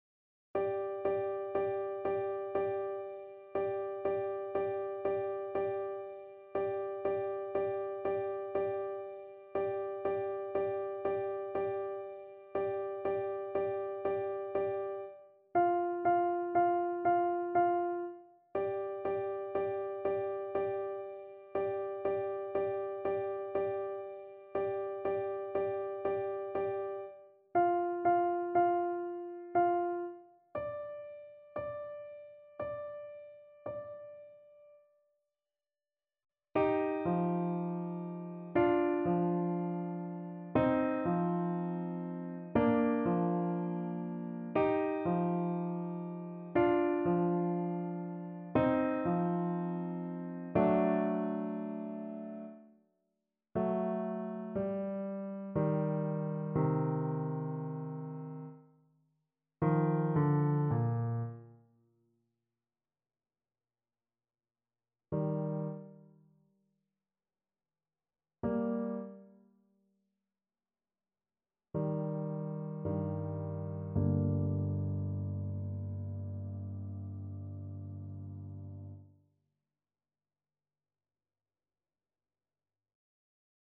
Andante sostenuto =60
3/4 (View more 3/4 Music)